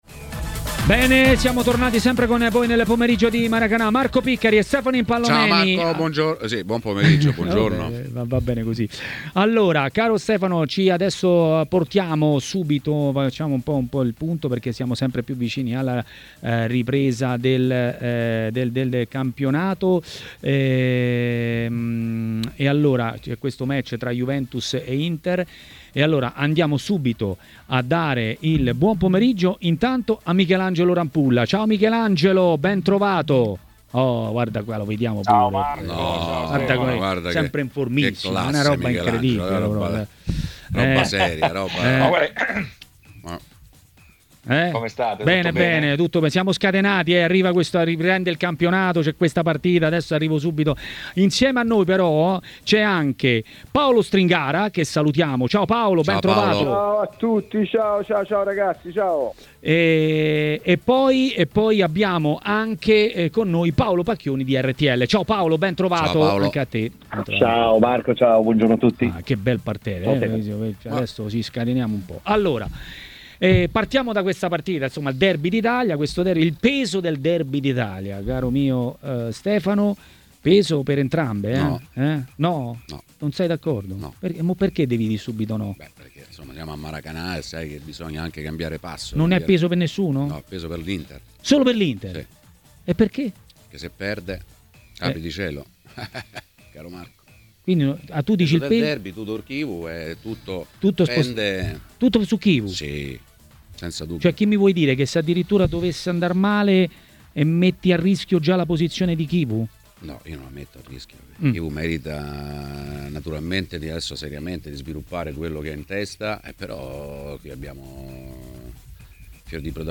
A intervenire in diretta a TMW Radio, durante Maracanà, è stato l'ex portiere Michelangelo Rampulla.